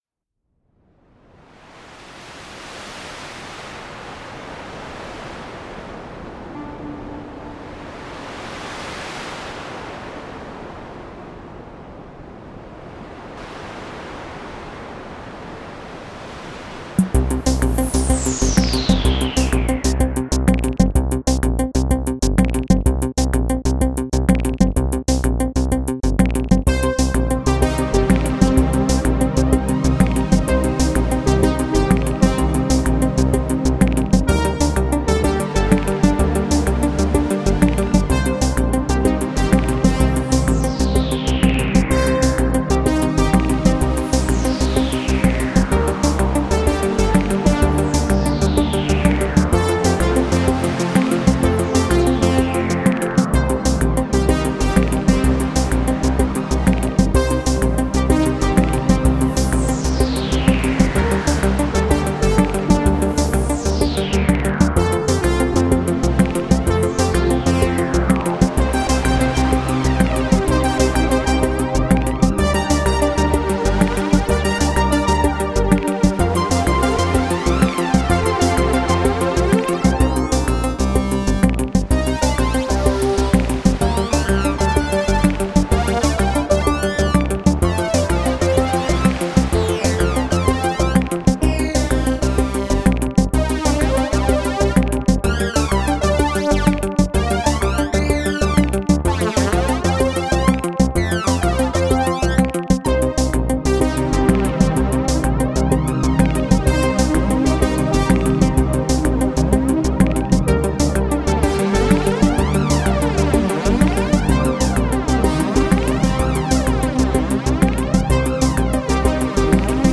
which I found from a sample pack of vintage drum machines.
and an FM lead type thing all show up.